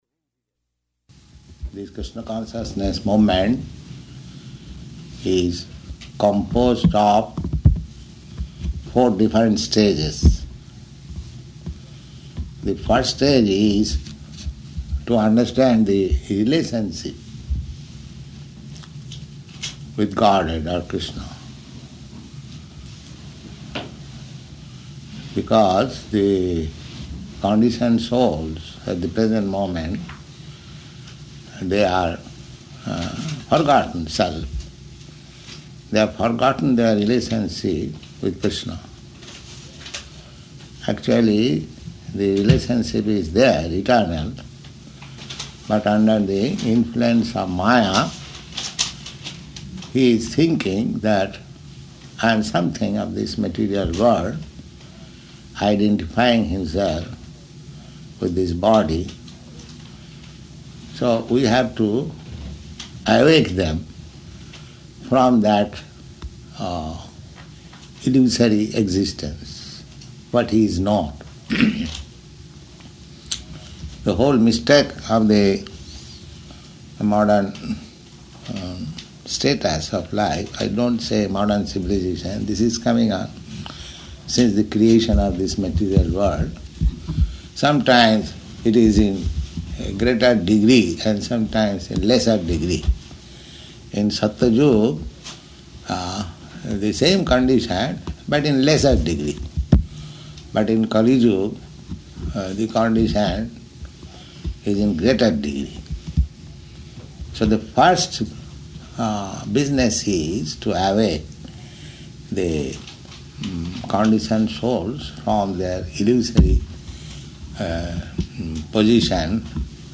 Discussion with BTG Staff --:-- --:-- Type: Conversation Dated: December 24th 1969 Location: Boston Audio file: 691224DC-BOSTON.mp3 Prabhupāda: This Kṛṣṇa consciousness movement is composed of four different stages.